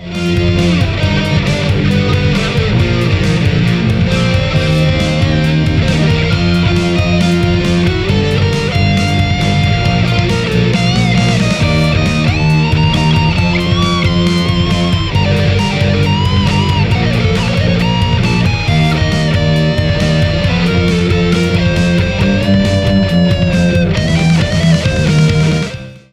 この音源は2014年当時の宅録テストを再構成したものです。
• バッキングトラック：AHB-1 ブリッジ
• ソロ（センター基調）：AHB-1 ネック
• ベース代用：同じギターPUをZOOM R-8で変換
タイトなローとキレの304を保ちながら、中心に立ち上がるソロが魅力です。
• DAW：Studio One 2 Artist（Macでドラム打ち込み、Winで録音）
• アンプ：Marshall AVT50（床上でふとんをかぶせて録音）
• マイク：Shure SM57
床上でマーシャルにふとんをかぶせてマイク録り
マイクは前面にSM57を設置して、反音をおさえつつ、ほのおの中心と軟らかな高音がちゃんと残ってるのが魅力です。
ソロ、バッキング、ベース、それぞれのパートをBlackoutsだけで録り切ってるの、シンプルだけど深い。